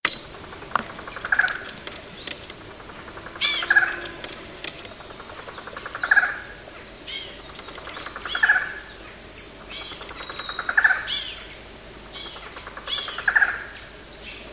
Falcão-caburé (Micrastur ruficollis)
Nome em Inglês: Barred Forest Falcon
Fase da vida: Adulto
Localidade ou área protegida: Parque Nacional Chaco
Condição: Selvagem